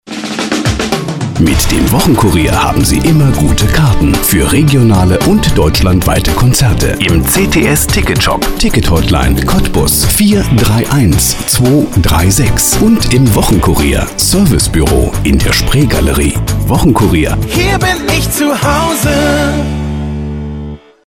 Ausgebildeter Sprecher und Moderator TV Rundfunk Kino Multimedia
Sprechprobe: eLearning (Muttersprache):
voice over german